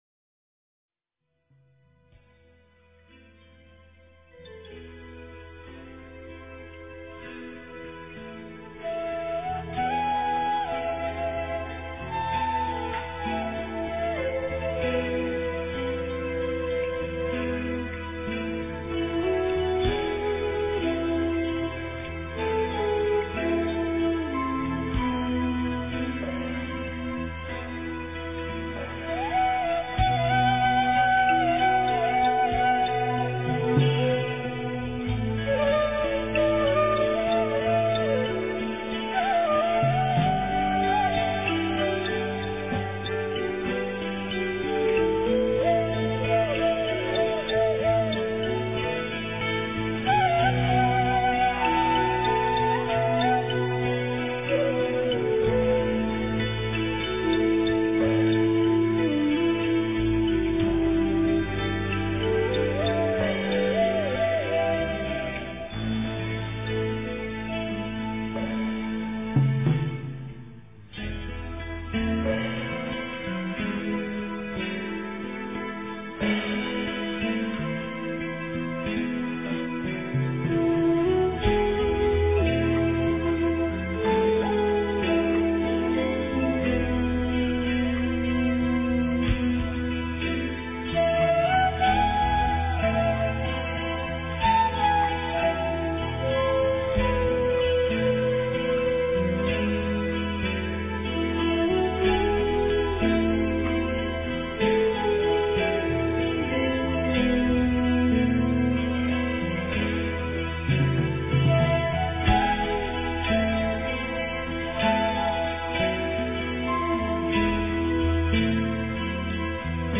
标签: 佛音冥想佛教音乐